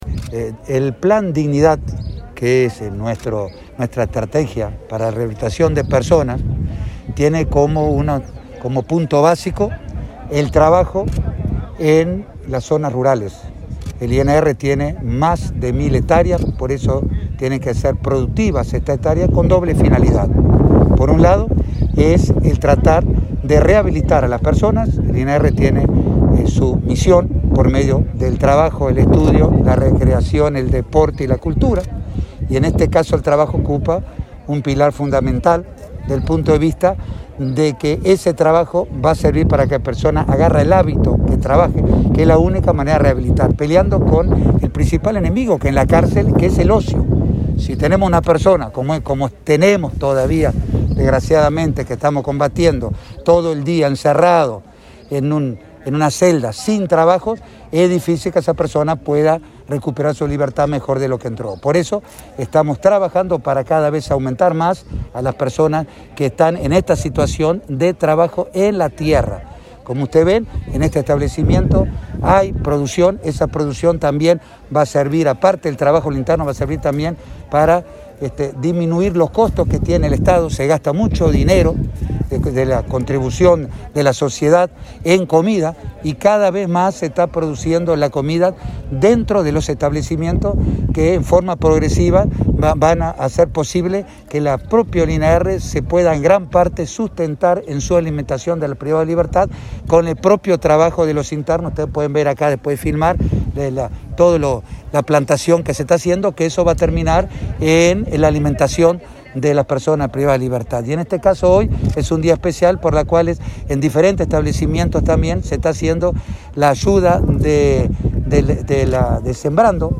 Declaraciones a la prensa del director del INR, Luis Mendoza
Declaraciones a la prensa del director del INR, Luis Mendoza 19/08/2021 Compartir Facebook X Copiar enlace WhatsApp LinkedIn En el marco de la inauguración de una huerta del programa Sembrando, en la unidad carcelaria n.° 6 de Paysandú, el director del Instituto Nacional de Rehabilitación (INR), Luis Mendoza, dialogó con la prensa.